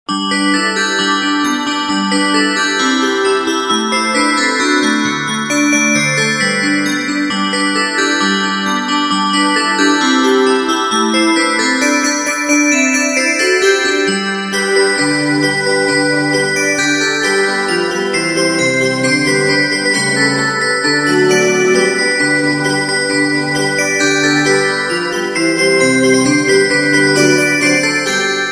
Новогодние рингтоны